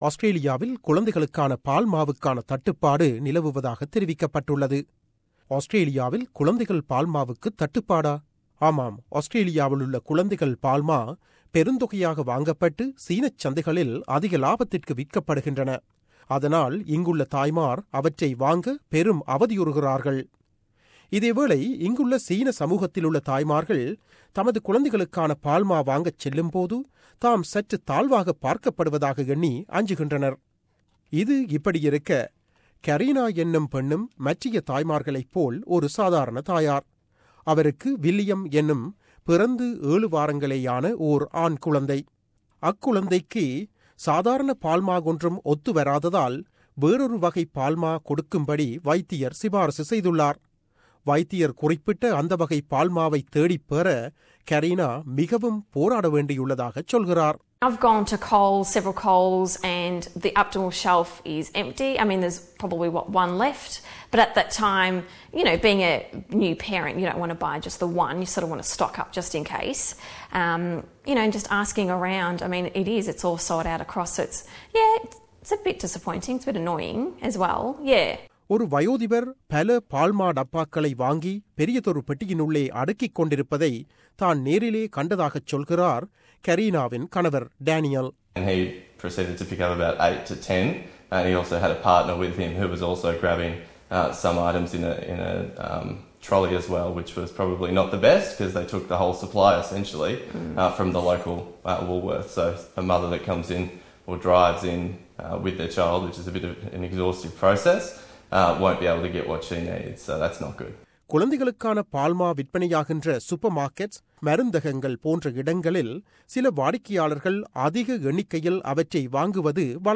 செய்தி விவரணம்